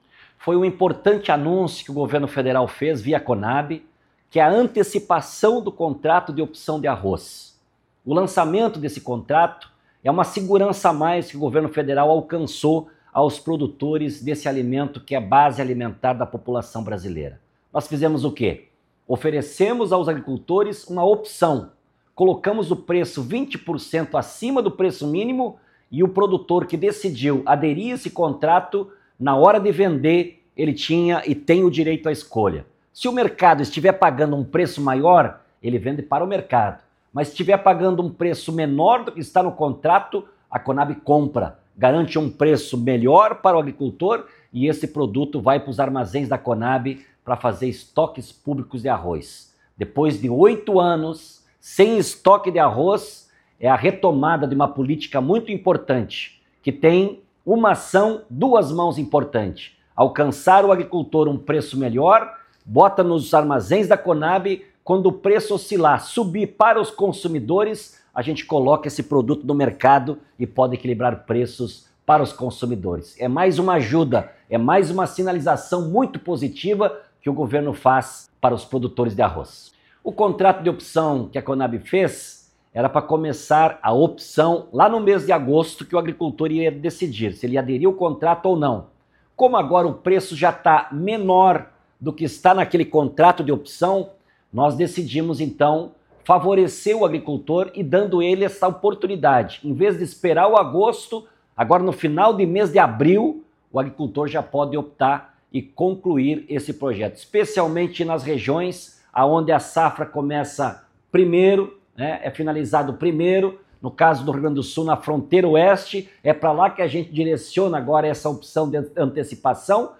Sonora: Edegar Pretto, presidente da Conab, fala sobre a antecipação da execução dos contratos de opção de venda de arroz:
Sonora-Edegar-Pretto-presidente-da-Conab-Antecipacao-dos-contratos-de-opcao-de-venda-de-arroz.mp3